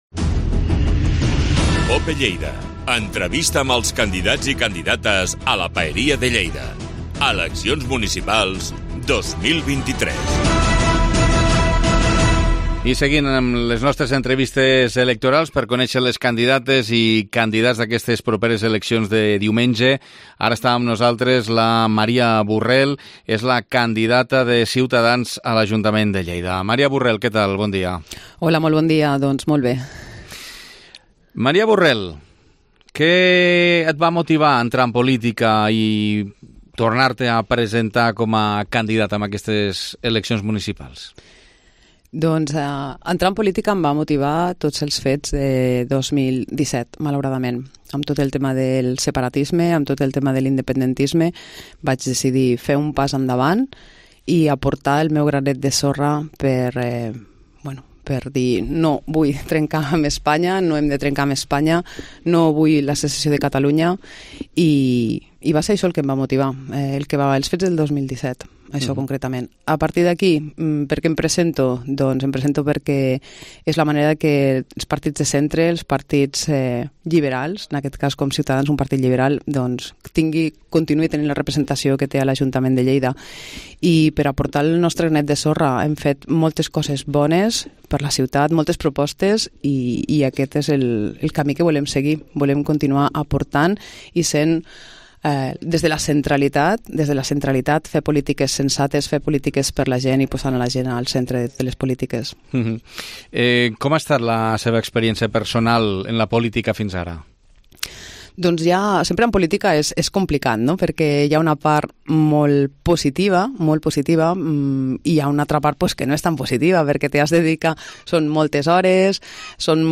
Entrevista Campanya Electoral 2023